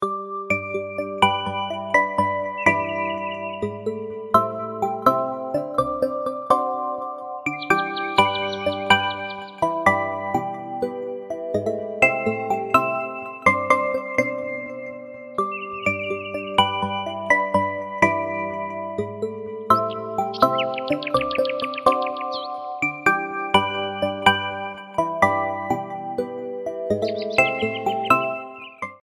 Blues Ringtones